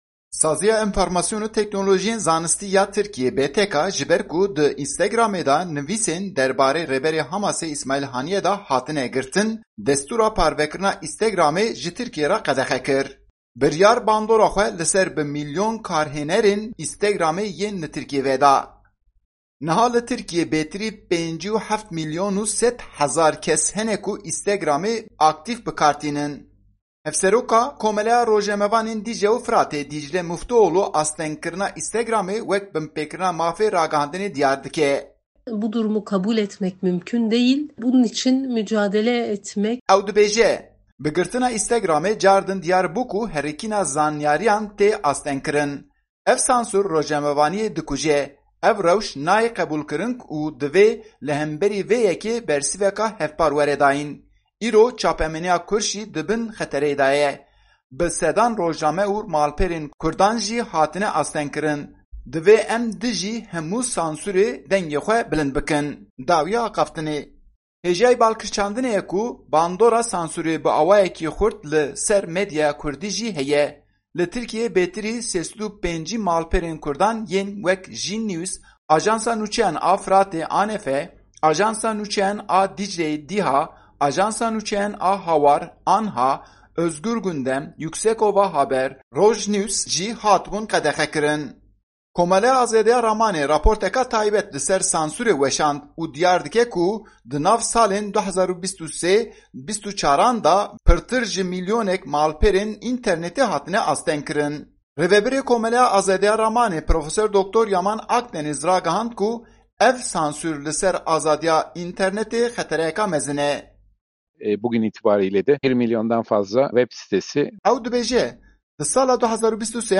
Raporta